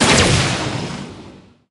roborocket01.ogg